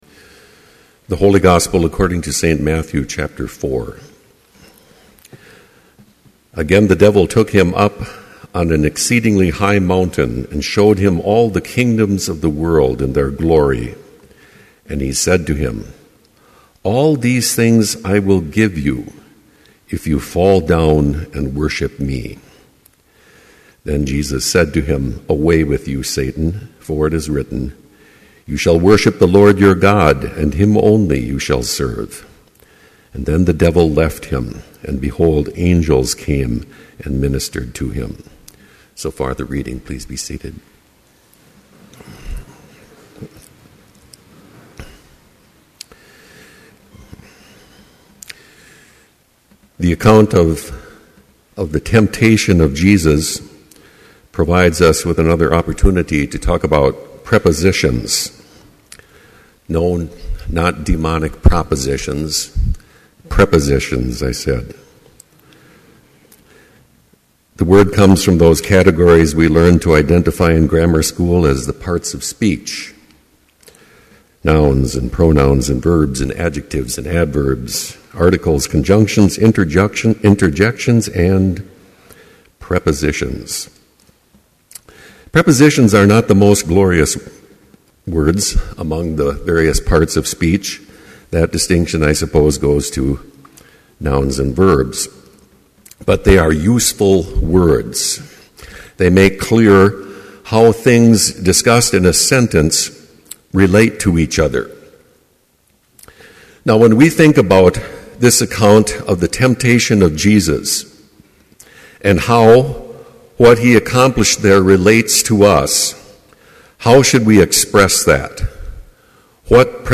Chapel worship service held on February 29, 2012, BLC Trinity Chapel, Mankato, Minnesota
Complete service audio for Chapel - February 29, 2012